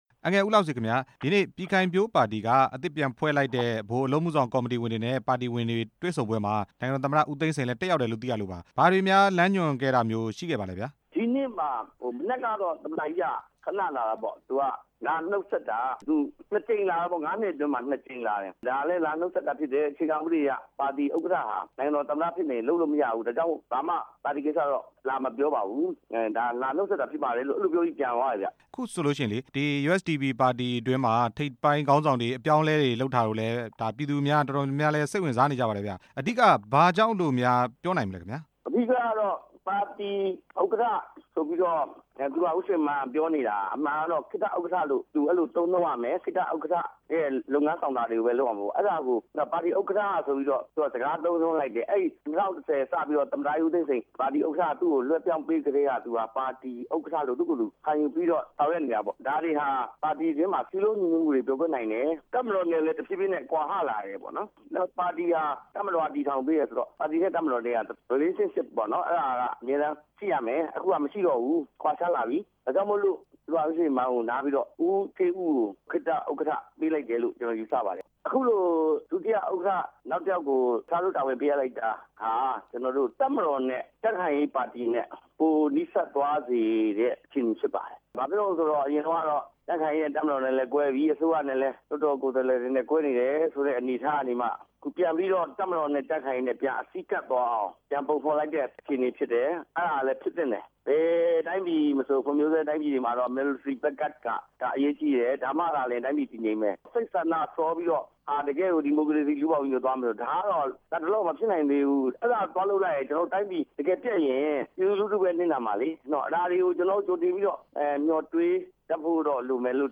ဦးလှဆွေကို မေးမြန်းချက်